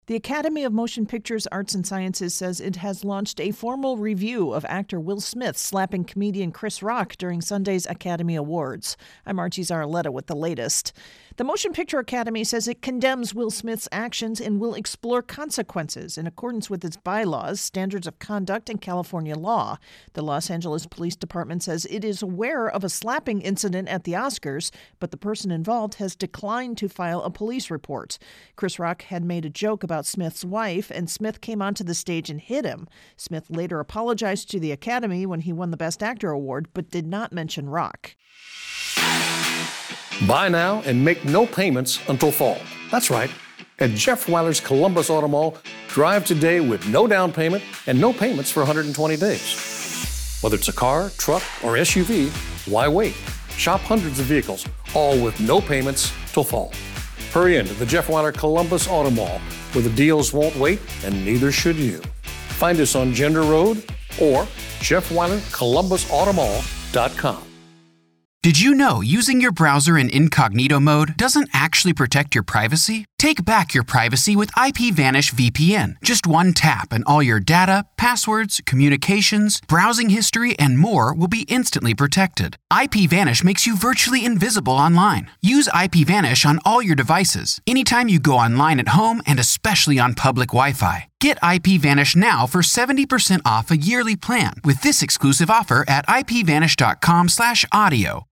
intro & voicer for Oscars-Will Smith-Academy Review